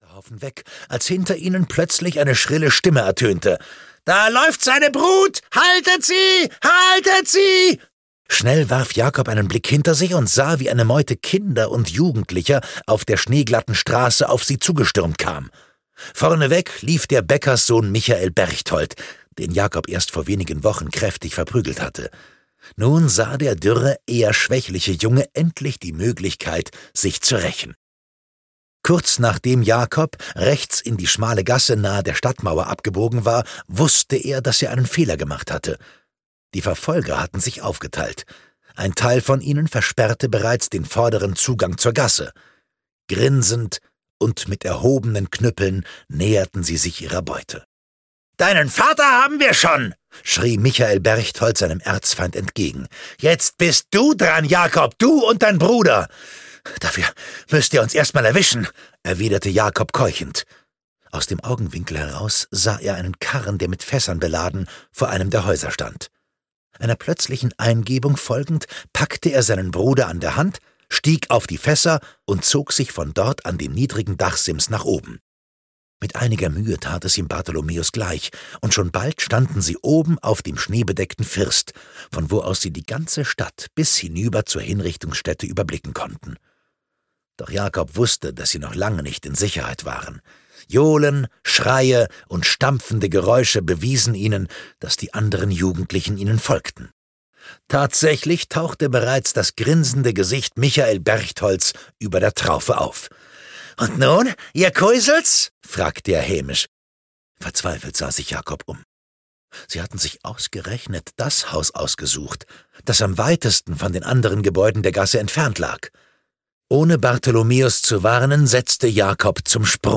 Die Henkerstochter und der Teufel von Bamberg (Die Henkerstochter-Saga 5) - Oliver Pötzsch - Hörbuch